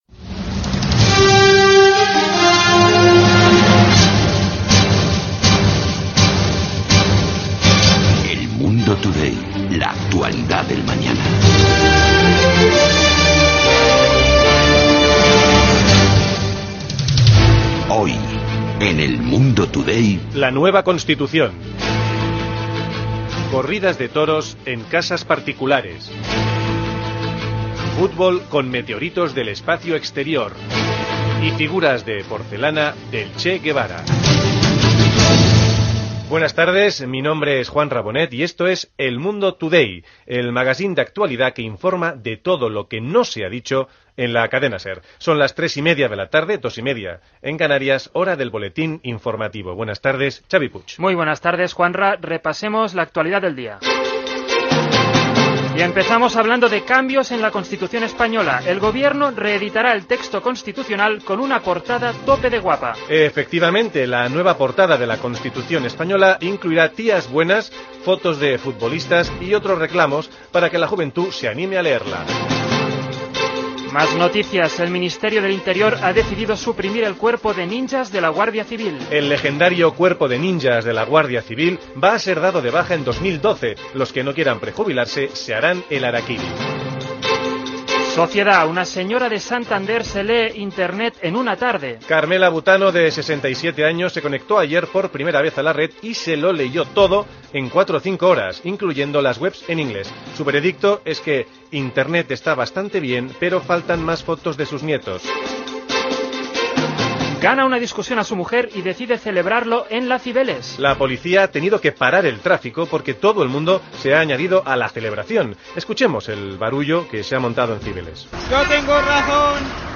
Careta del programa, sumari, hora, butlletí informatiu, connexió amb Bilbao on ha caigut un meteorit. Publicitat fictícia, secció "La tertulia" sobre la tauromàquia domèstica
Entreteniment